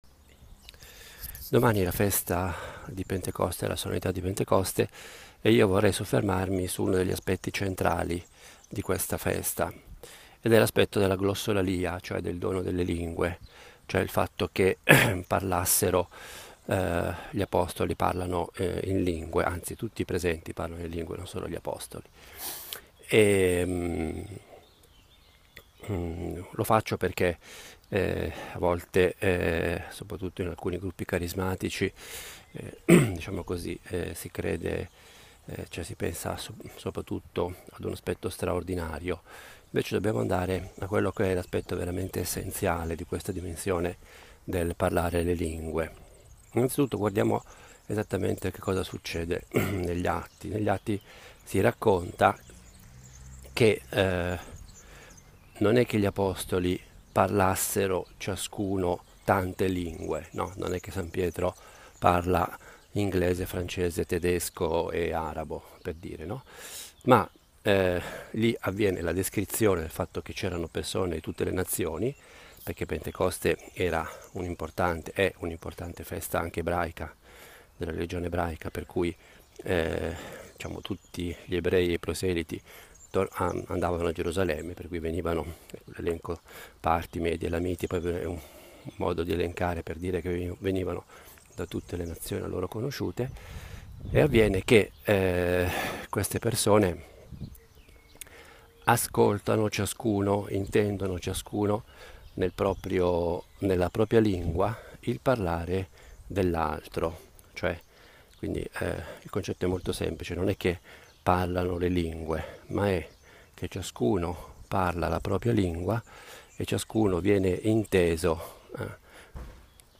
Pausa caffè a Nazareth è una riflessione breve, di otto minuti, sul vangelo della domenica. Una meditazione nella quale cerco di collegare il vangelo con la vita quotidiana e con la nostra prosa più normale: la frase di un giornale, le parole di una canzone. Vorrei avesse il carattere piano, proprio di una conversazione familiare. Io la intendo come il mio dialogo personale – fatto ad alta voce – con Dio e con la Madonna.